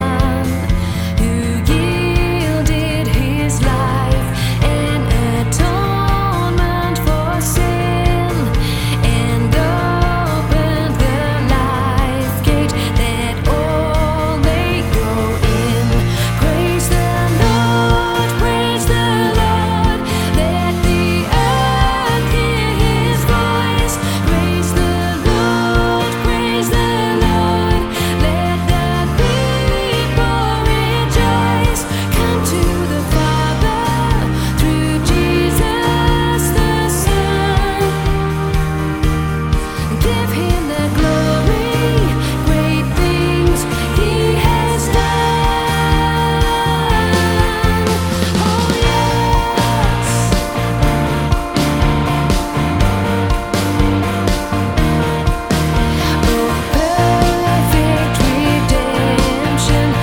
Live Worship...
• Sachgebiet: Praise & Worship